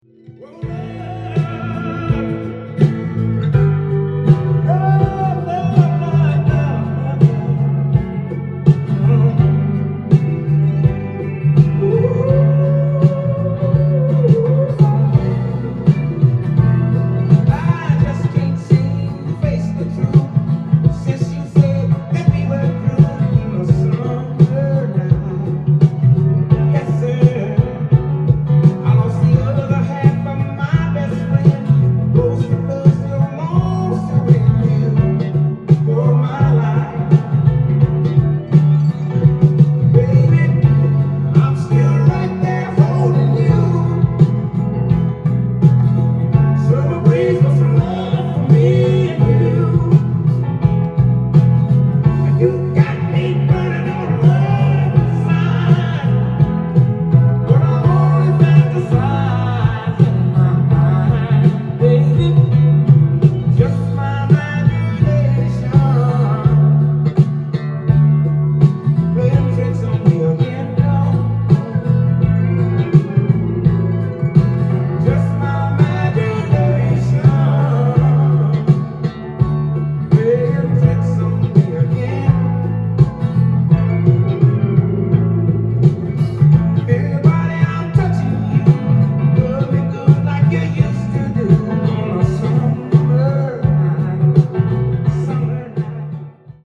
店頭で録音した音源の為、多少の外部音や音質の悪さはございますが、サンプルとしてご視聴ください。
名ソウル・シンガー